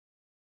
Ein Pfarrer und ein Imam über Versöhnung (Express)
Und deswegen habe wir in diesem Jahr, in dem wir häufig auf der Suche nach Würde und Weisheit waren und sie nicht unbedingt immer fanden, zwei Geistliche eingeladen.